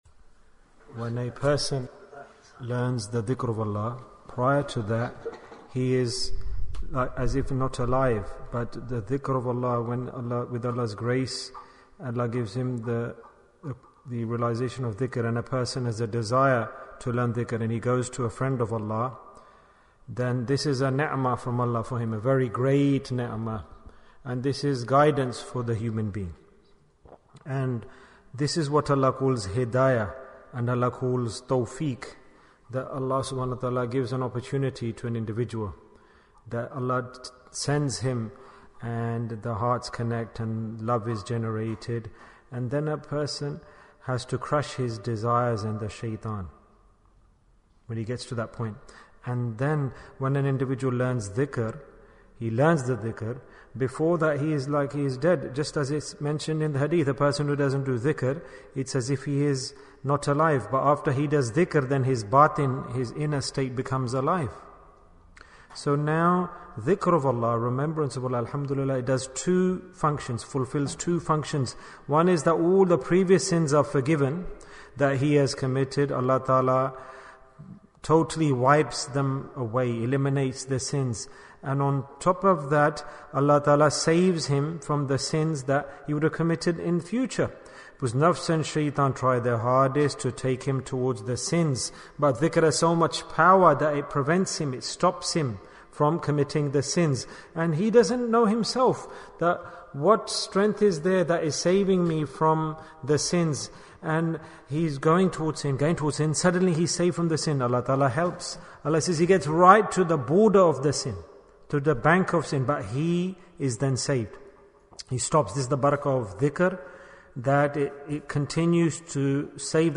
Bayan, 6 minutes